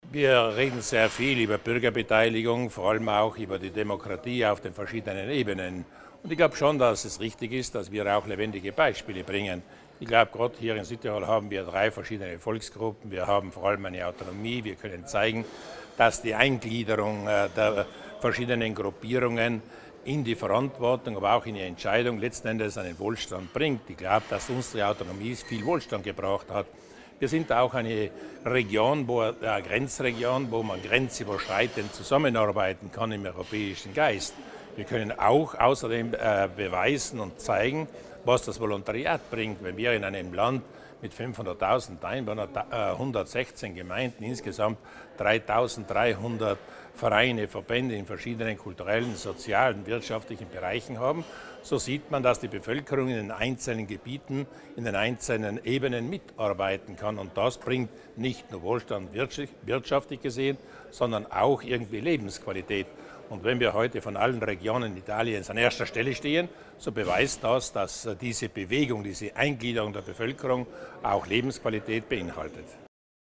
LPA - Zwei Tage lang trifft sich Europa in Bozen: Noch bis morgen (9. Juli) tagt auf Einladung der Europaregion Tirol-Südtirol-Trentino die Fachkommission für Unionsbürgerschaft, Regieren, institutionelle Fragen und Außenbeziehungen im EU-Ausschuss der Regionen im Hotel Sheraton in Bozen.
EVTZ-Präsident Landeshauptmann Luis Durnwalder, selbst Gründungsmitglied des Ausschusses der Regionen, begrüßte heute über 100 Mitglieder und Experten aus 28 Ländern der Europäischen Union.